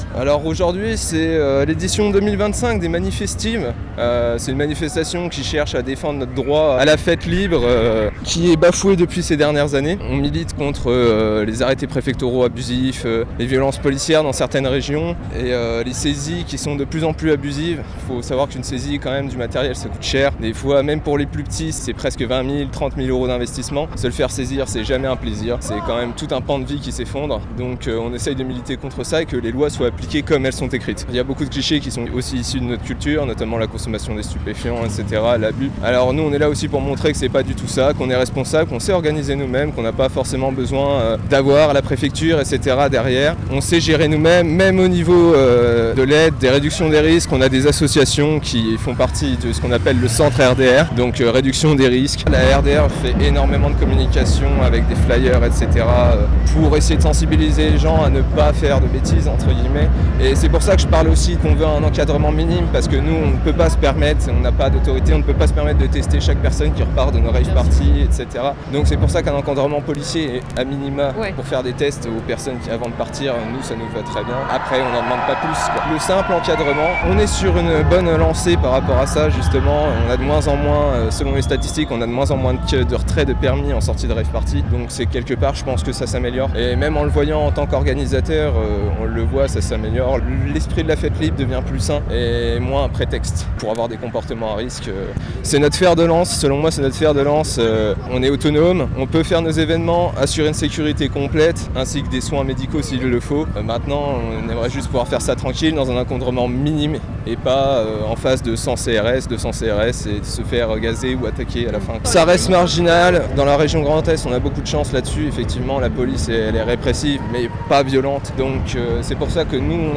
Une proposition de loi veut renforcer la pénalisation de ces événements considérés comme sauvages. A Nancy, la manifestation a réuni plusieurs centaines de teufeurs qui entendaient défendre cette forme de culture.